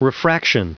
Prononciation du mot refraction en anglais (fichier audio)
Prononciation du mot : refraction